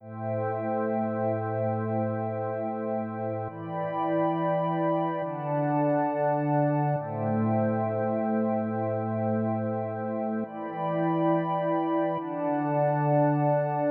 I load up the Autum preset from the Subtractor’s own pad library.
I create an RV7 reverb to give some space and some feeling to the instrument.
Load up any kind of pad pattern and play it here is mine:
eq effect dry.mp3